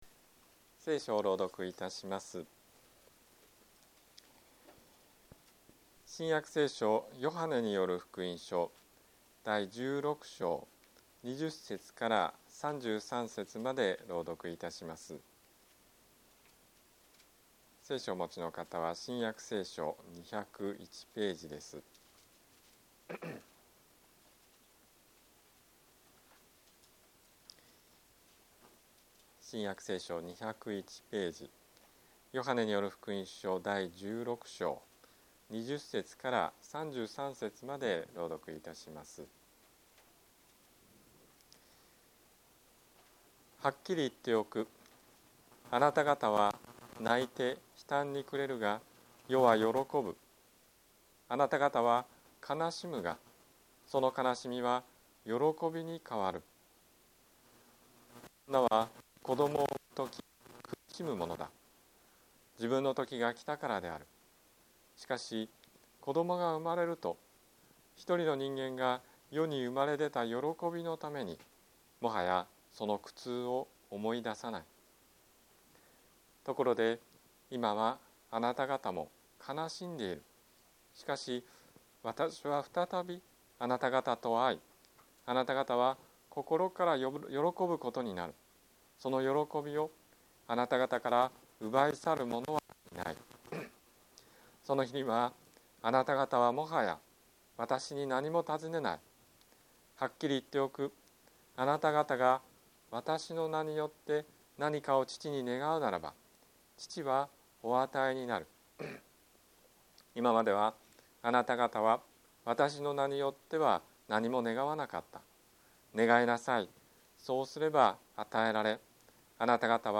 日曜 朝の礼拝
説教